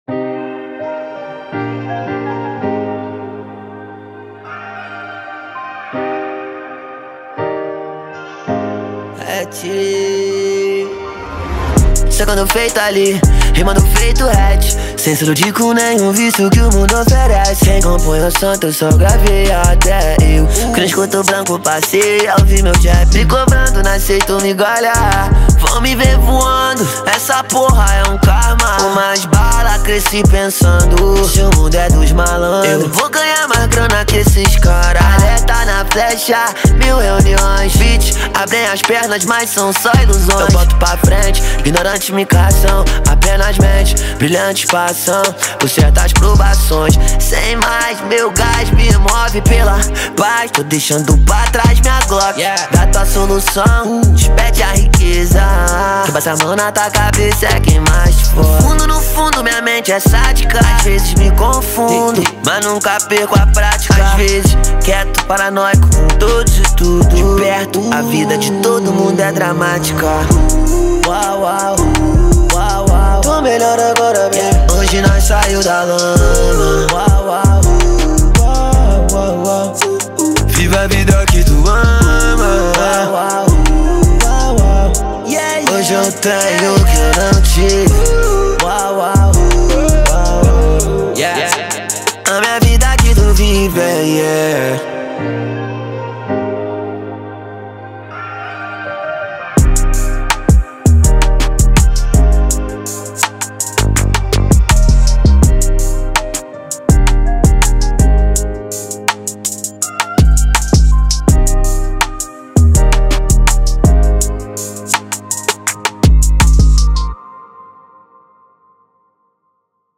2024-07-27 12:11:54 Gênero: Funk Views